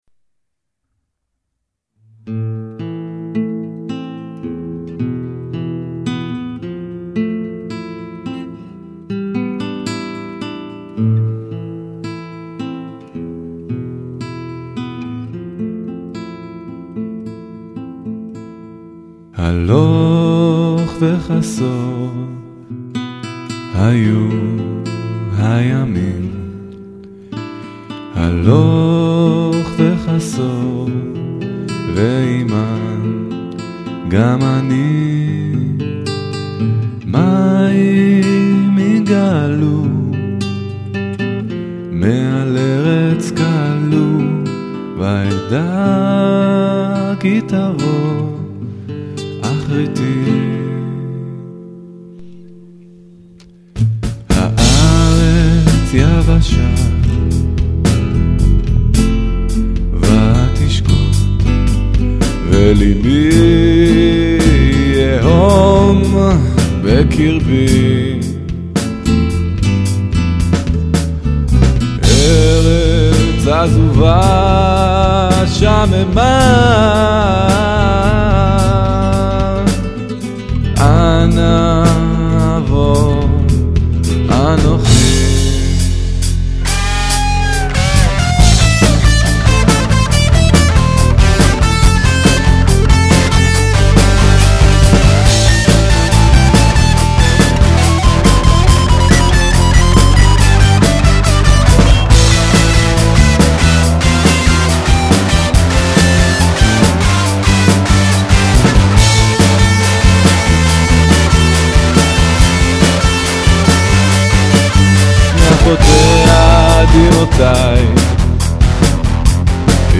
רק איכות הסולו ובמיוחד הטון של הדיסטורשן לדעתי קצת מקלקל.
(אולי בגלל האיכות של ההקלטה!)
הקול חזק מידי...